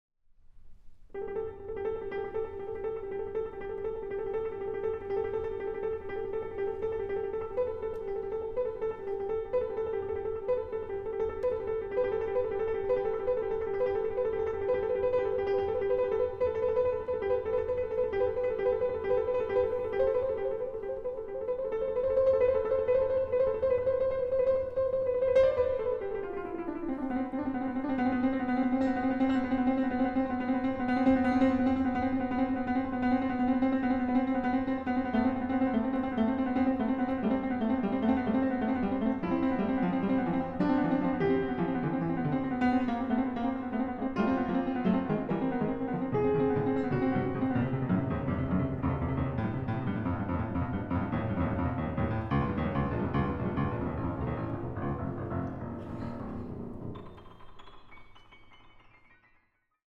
Piano
Recording: Großer Saal, Gewandhaus Leipzig, 2025